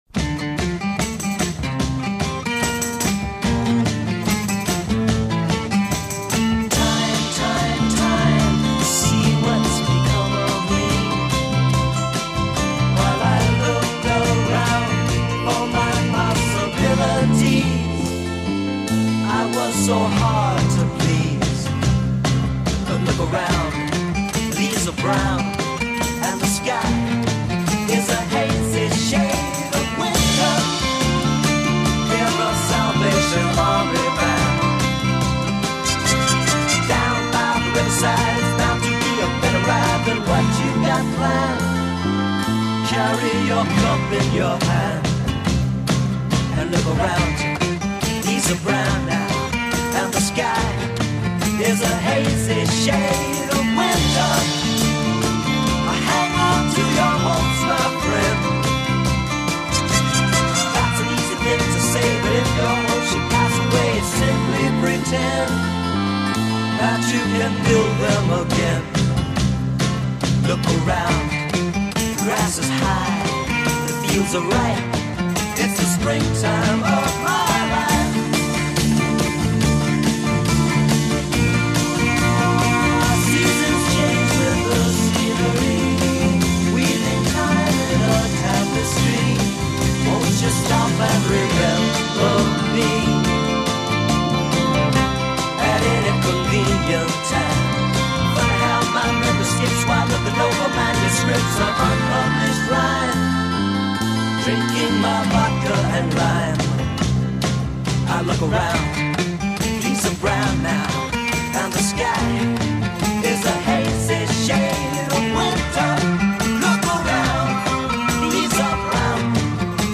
To będzie jeden z tych odcinków, gdzie będzie można usłyszeć więcej muzyki, poczynając od jazzu, przez rock, punk a kończąc na hip-hopie